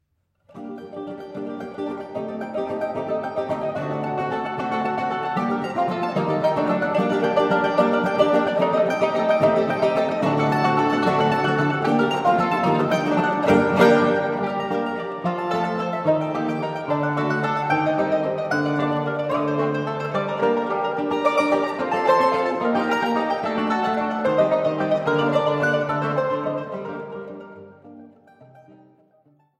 Laute & Mandoline
Liuto Forte, Konzertgitarre